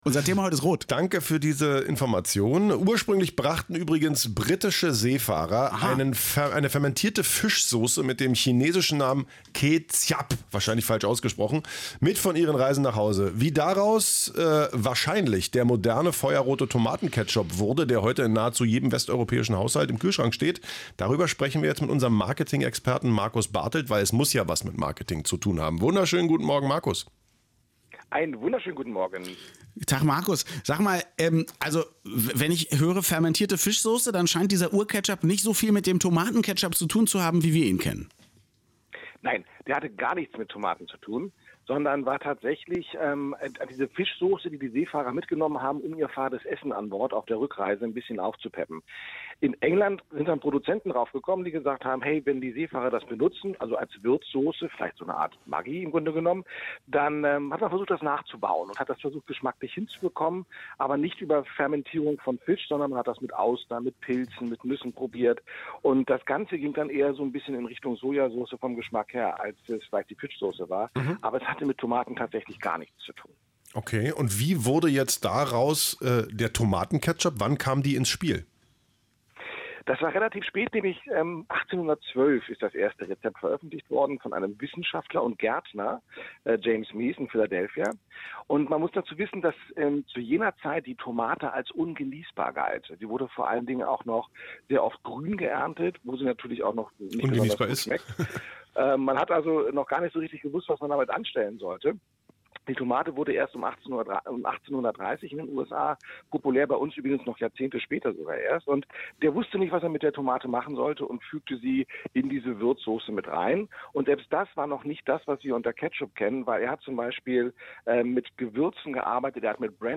Daher gab es das „radioeins„-Interview diesmal nur am Telefon:
(die Qualität ist bescheiden, aber wahrscheinlich kann ich noch vor Weihnachten den Take mit einer besseren Variante austauschen)